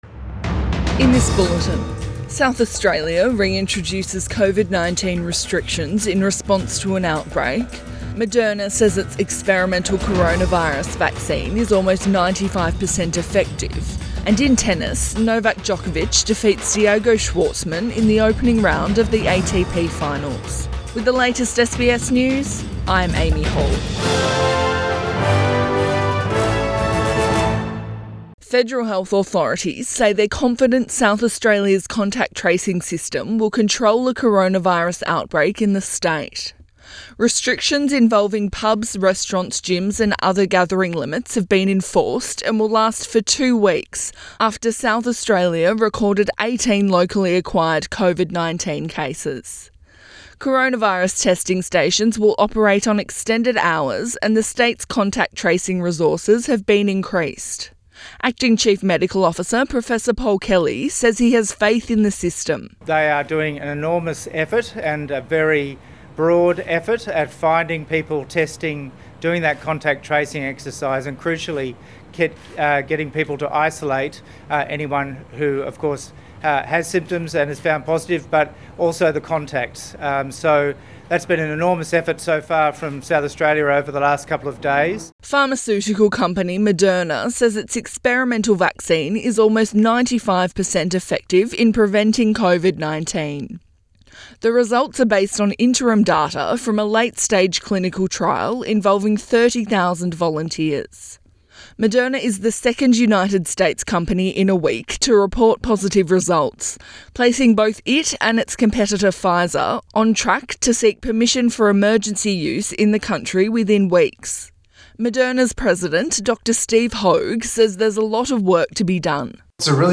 AM bulletin 17 November 2020